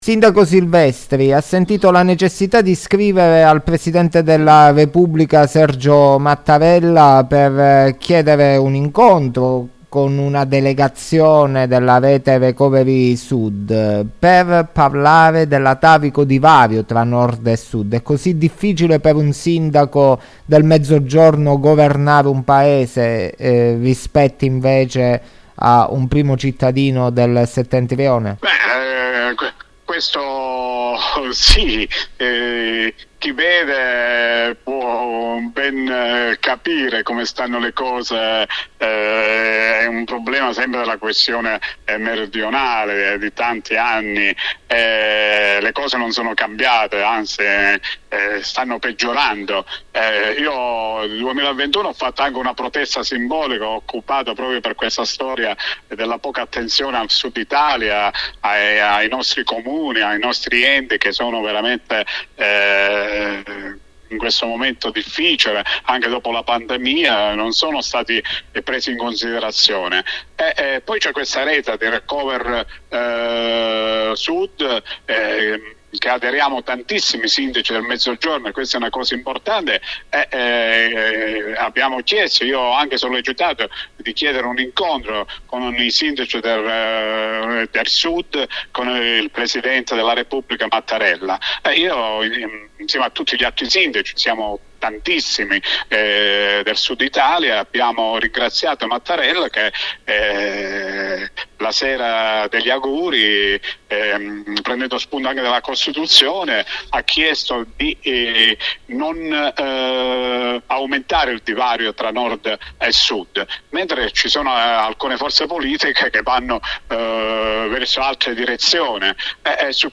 “AUTONOMIA DIFFERENZIATA”. CHI DICE NO! INTERVISTA AL SINDACO DI VERBICARO FRANCESCO SILVESTRI
“No all’autonomia differenziata”. Lo dice chiaramente il sindaco di Verbicaro, Francesco Silvestri, da noi intervistato nelle scorse ore.
SILVESTRI_INTERVISTA.mp3